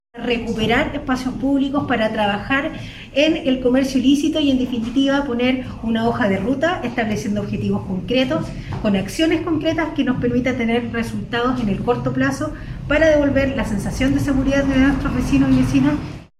01-DELEGADA-REGIONAL-Hoja-de-ruta.mp3